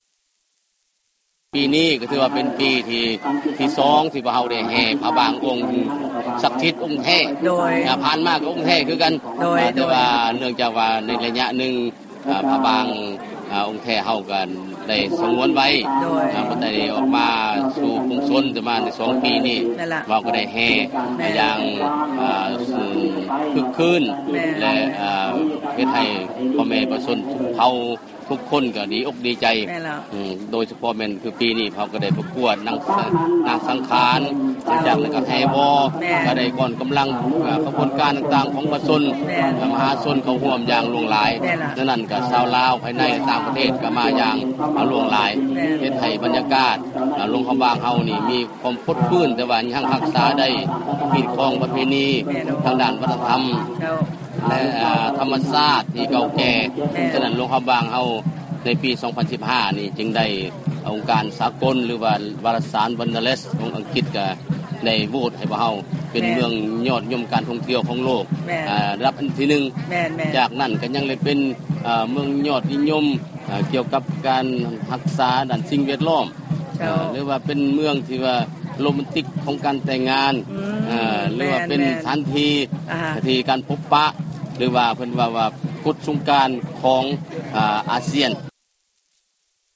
ຟັງການສຳພາດ ພະນະທ່ານ ເຈົ້າແຂວງ ຄຳແພງ ໄຊສົມເພັງ